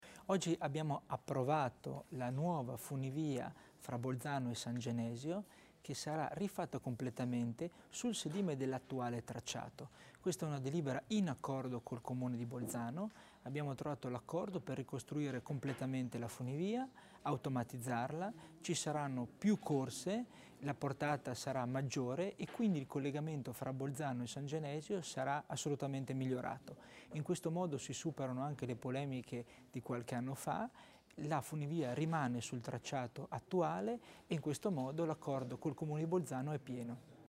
L'Assessore Tommasini illustra il nuovo progetto della funivia di San Genesio